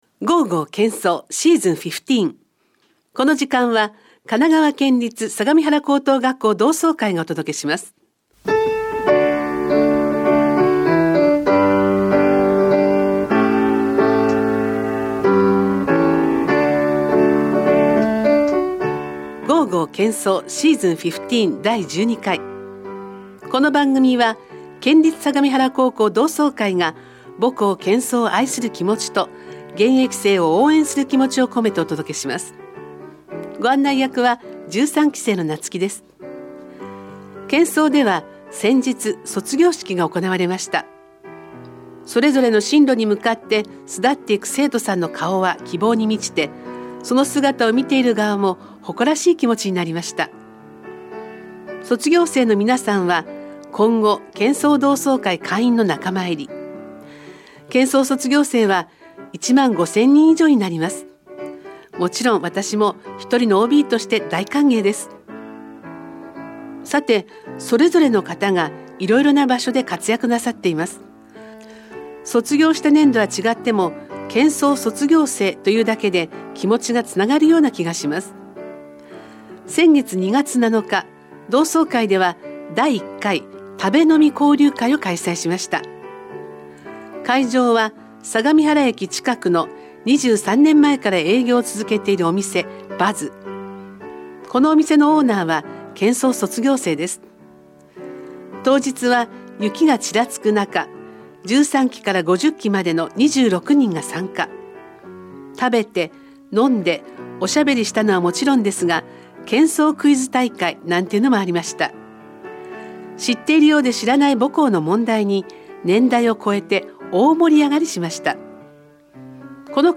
（※お願い）県相同窓会のラジオ番組 『 ＧＯＧＯ！県相 』 の出演者を募集しております。